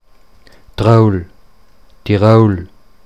Deutsch-mundartliche Form
[troul], [tiˈroul], [dorf tiˈroulər]
Tirol_Mundart.mp3